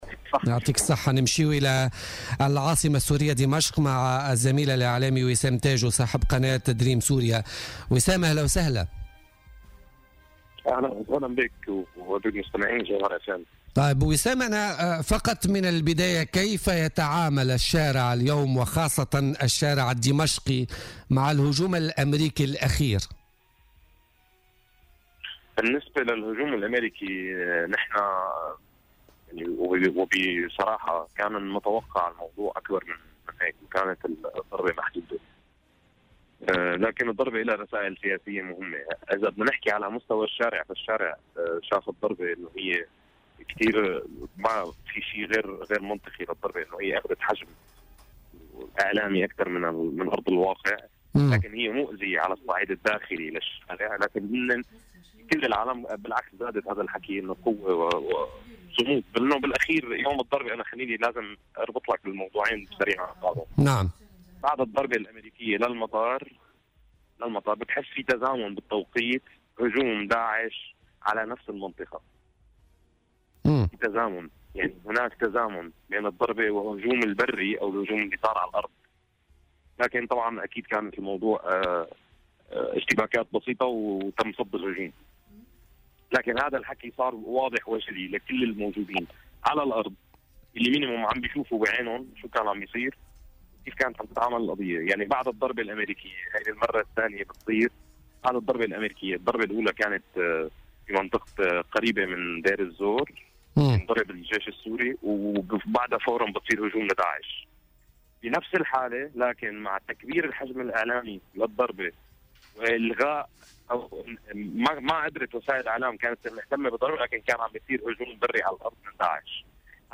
وأوضح في اتصال هاتفي بـ "الجوهرة أف أم" عبر "بوليتيكا"، أن العمليتين كانت متزامنة بين ضربة جوية من الجانب الأميركي والأخرى برية من طرف داعش فيما كانت الخسائر التي ألحقتها الضربة الأميركية بالجيش السوري بسيطة حيث أقلعت الطائرات من المطار بعد 8 ساعات فقط من ضرب قاعدة الشعيرات العسكرية في منطقة قريبة من دير الزور.